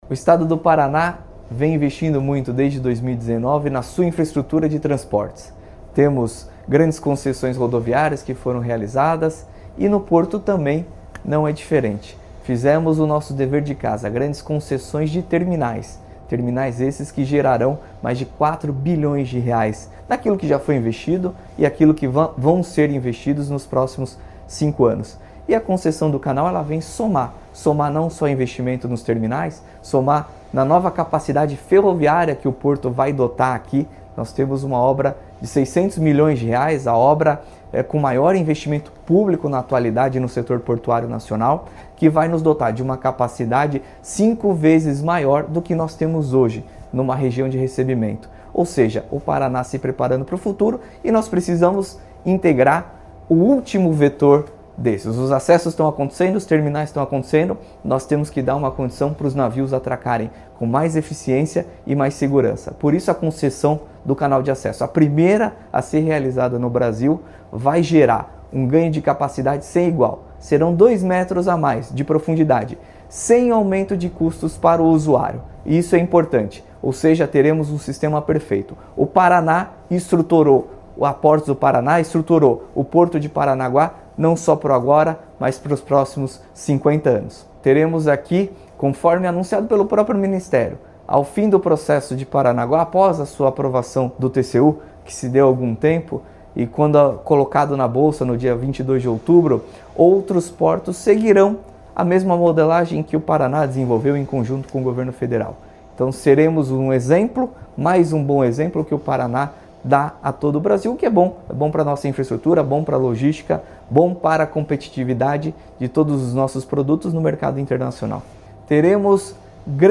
Sonora do diretor-presidente da Portos do Paraná, Luiz Fernando Garcia, sobre o Edital do leilão do Canal de Acesso do Porto de Paranaguá